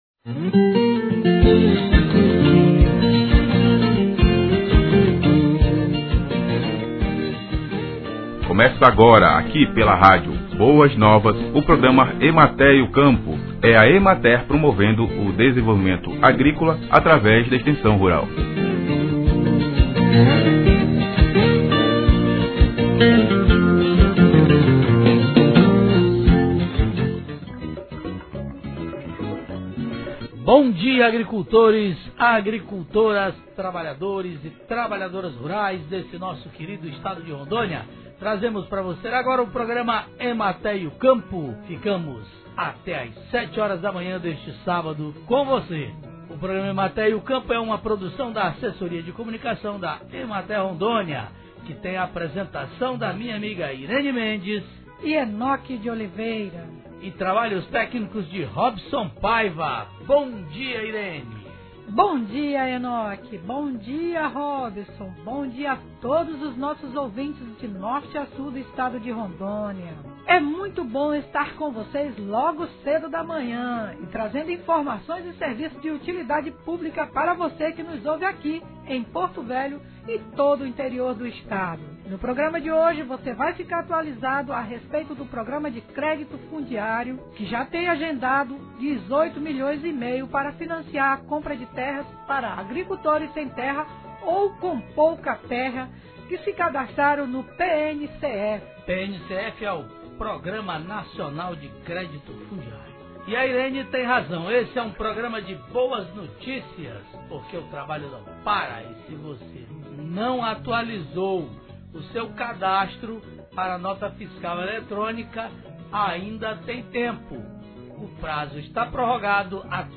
Programa de Rádio